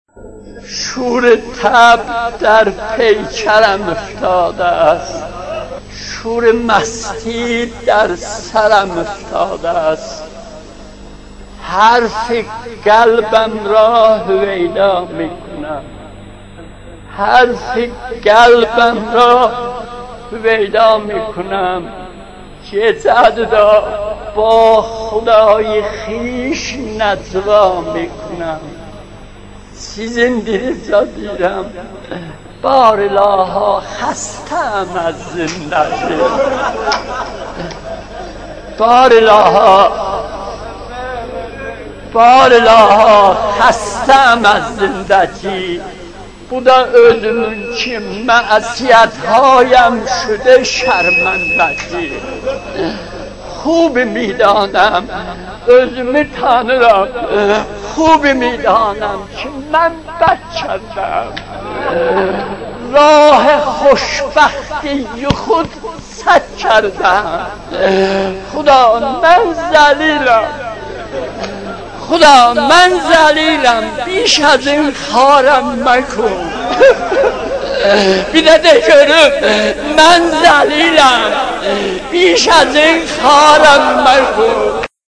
مناجات3.mp3